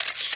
shutter.ogg